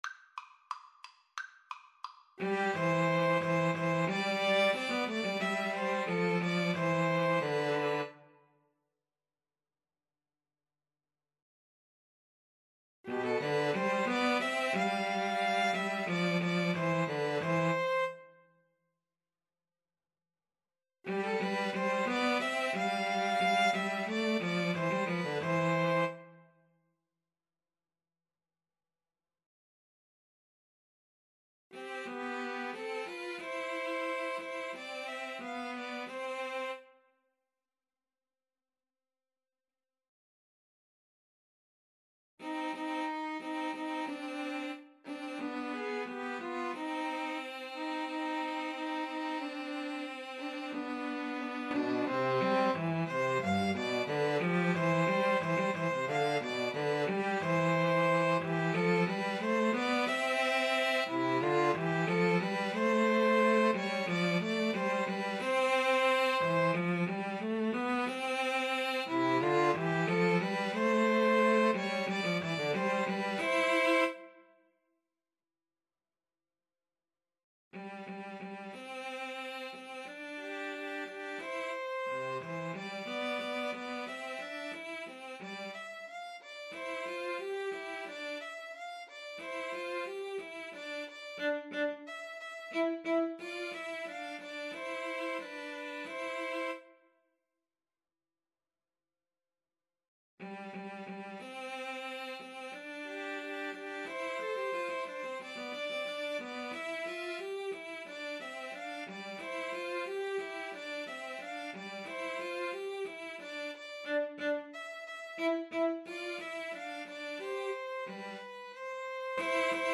Allegro =180 (View more music marked Allegro)
4/4 (View more 4/4 Music)
Classical (View more Classical Piano Trio Music)